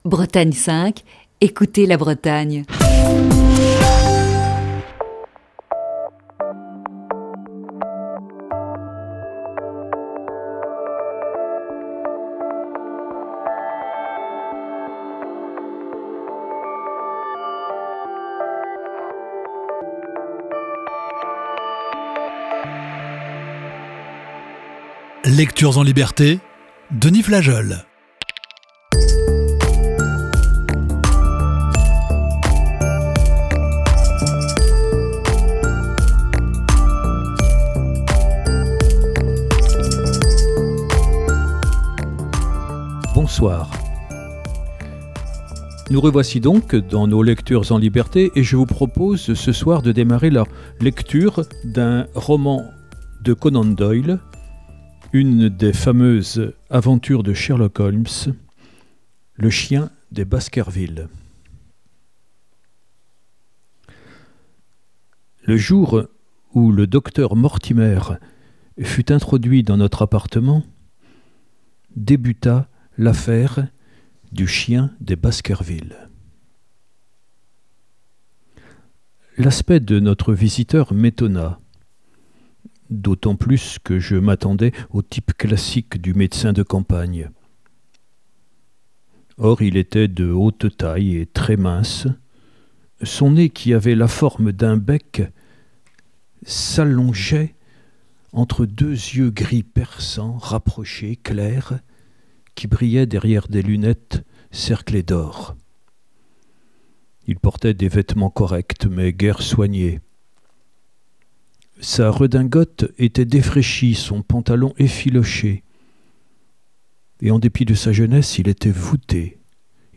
Voici ce lundi, la première partie de ce récit.